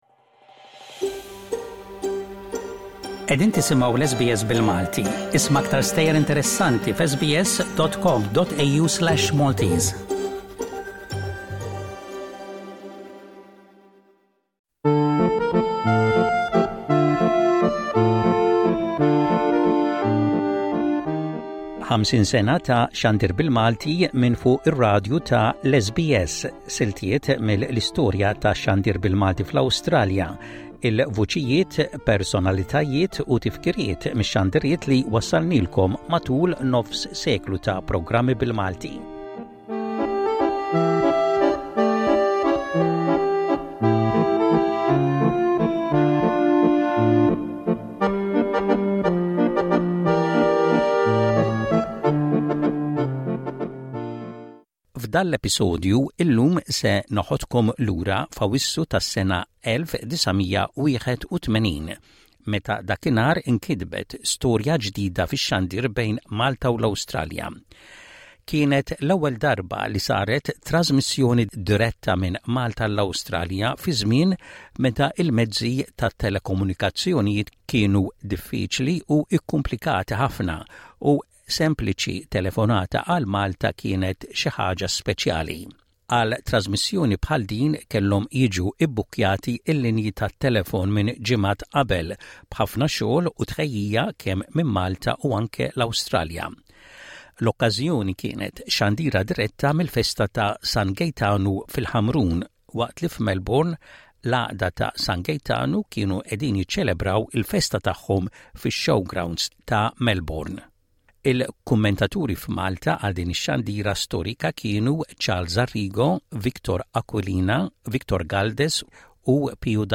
F’dawn l-episodji li bihom qegħdin infakkru għeluq il-50 sena ta’ xandir bil-Malti fuq l-SBS illum immorru lura f’Awwissu tal-1981 meta kellna l-ewwel xandira diretta minn Malta għall-Awstralja, fi żmien meta l-mezzi tat-telekomunikazzjonijiet kienu diffiċli ħafna. Ix-xandira saret mill-Ħamrun, fl-okkażjoni tal-festa ta’ San Gejtanu.